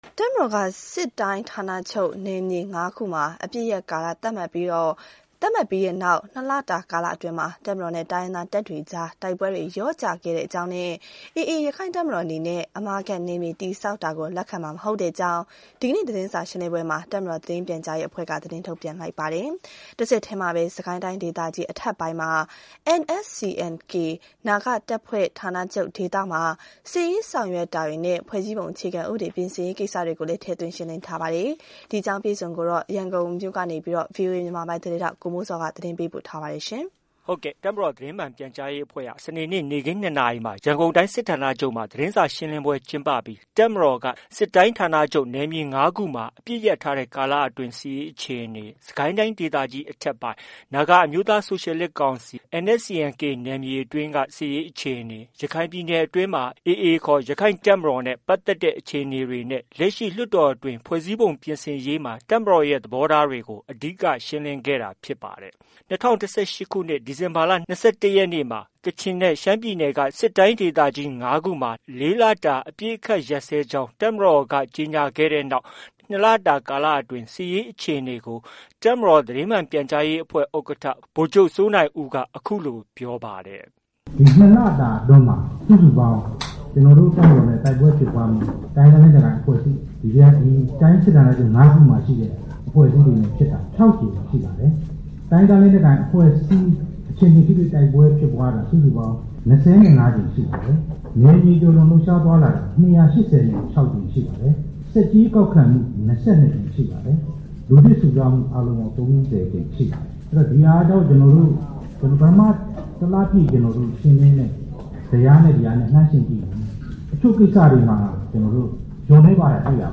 ၂၀၁၈ ခုနှစ် ဒီဇင်ဘာလ ၂၁ ရက်နေ့မှာ ကချင်နဲ့ ရှမ်းပြည်နယ်က စစ်တိုင်းဒေသကြီး ၅ ခု မှာ ၄ လတာအပစ်အခတ်ရပ်စဲကြောင်း တပ်မတော်က ကြေညာခဲ့တဲ့နောက် ၂ လကြာ ကာလအတွင်း စစ်ရေးအခြေအနေကို တပ်မတော်သတင်းမှန်ပြန်ကြားရေးအဖွဲ့ ဥက္ကဋ္ဌ ဗိုလ်ချုပ်စိုးနိုင်ဦးက အခုလို ပြောပါတယ်။